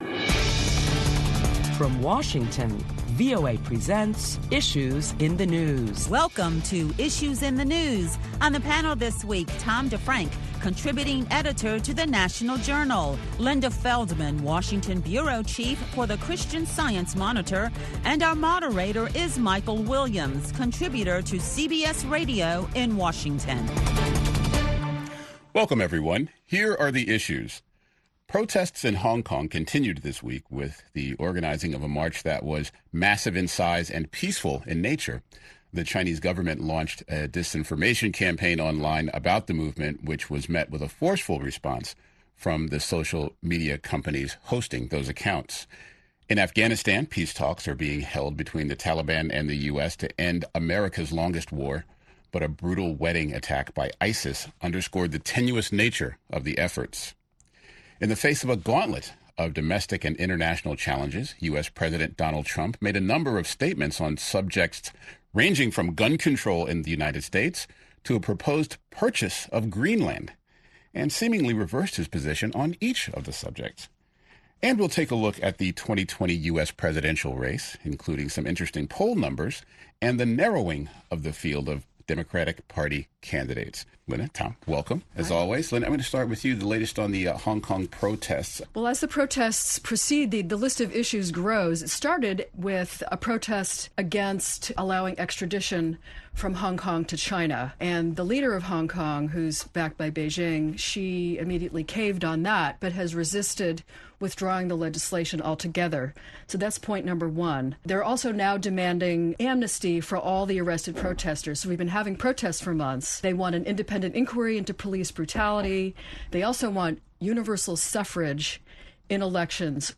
Listen to a panel of prominent Washington journalists as they analyze the latest top stories that include Beijing’s disinformation campaign against the popular Hong Kong democracy movement, and US President Donald Trump’s reversal on background checks for gun safety legislation.